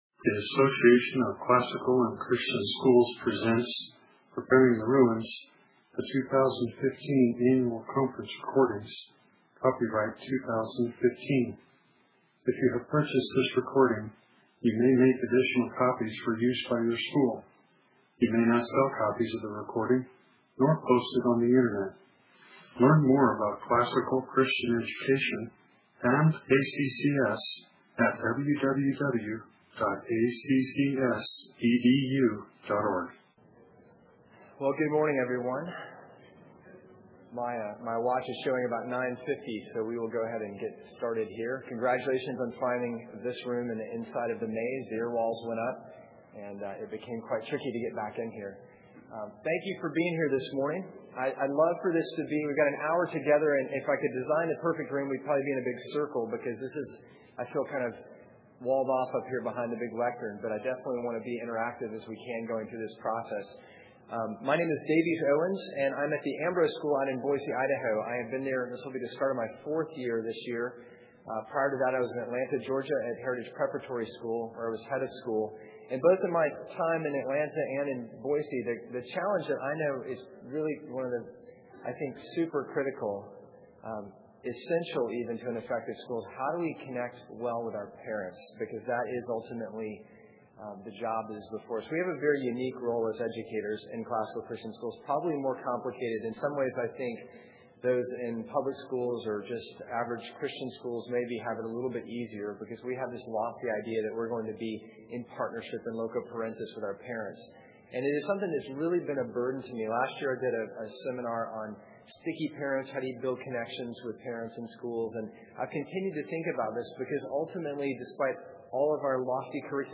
2015 Workshop Talk | :05:08 | Leadership & Strategic, Marketing & Growth